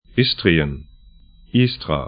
Aussprache
Istrien 'ɪstrĭən Istra